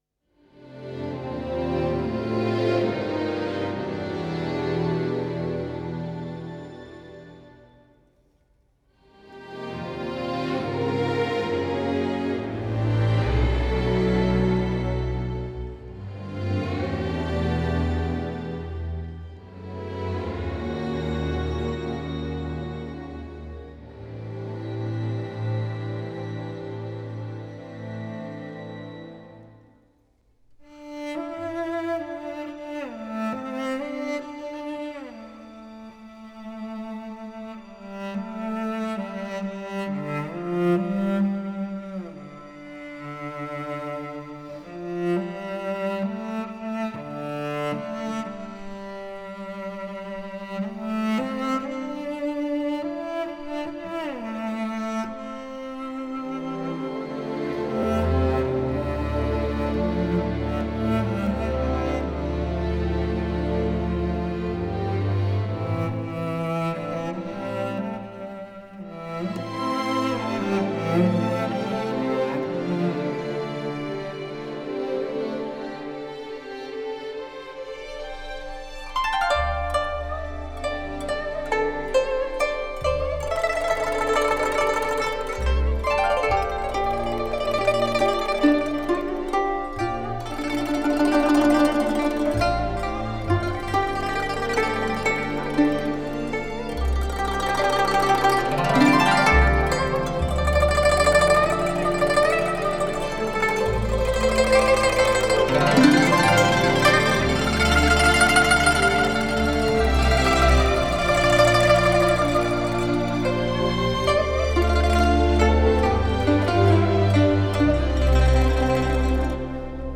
前所未有的民族交响与发烧人声于中央电视台一号录音棚交融，经典的韵味与现代的技术。
女声主唱
男声独唱
女声小合唱
古筝
笛子、箫
琵琶
二胡
录音棚：中央电视台一号棚